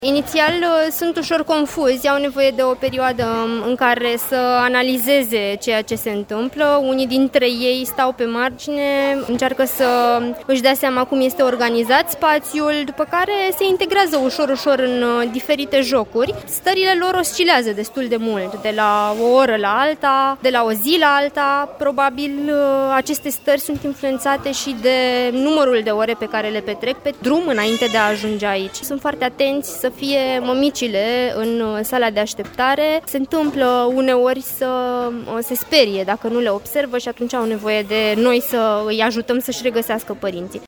Reportaj. În Gara de Nord, refugiații au timp să respire, iar copiii se pot juca din nou, înainte de a pleca spre o altă destinație | AUDIO